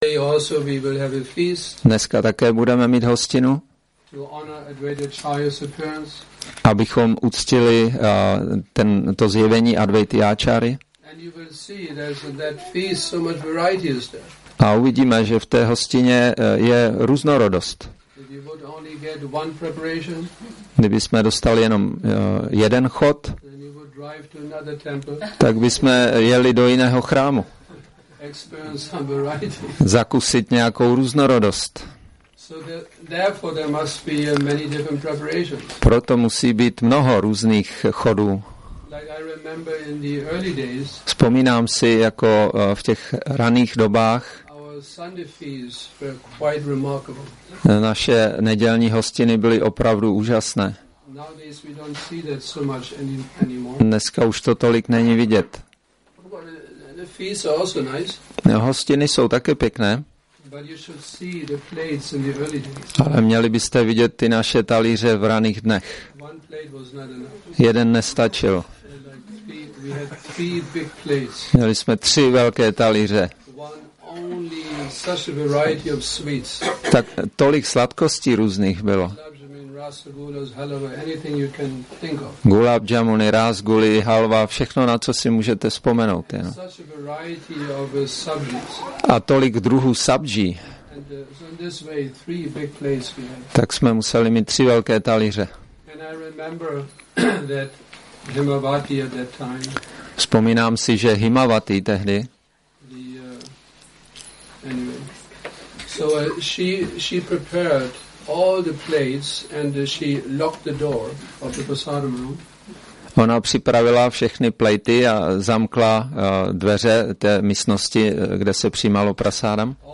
Přednáška Advaita acarya appearance day – Šrí Šrí Nitái Navadvípačandra mandir